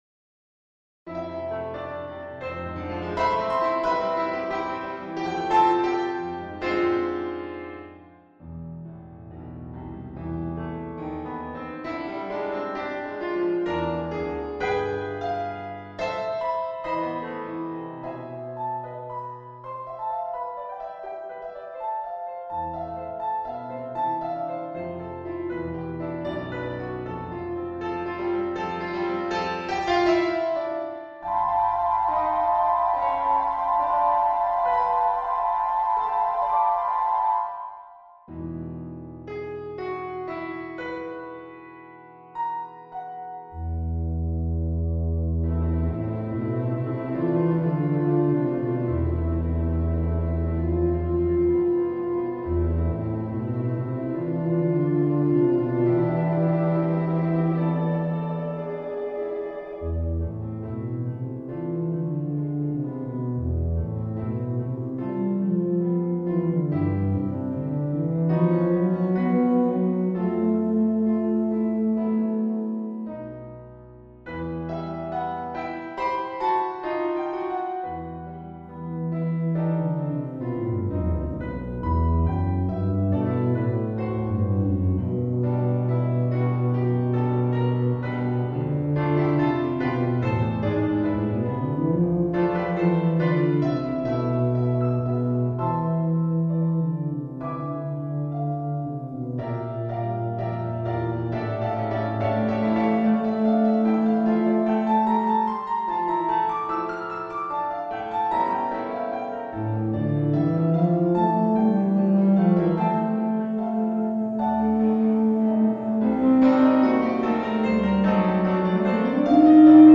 Movimiento único.
Tuba solista y banda.